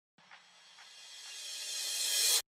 transition-c1107e61.mp3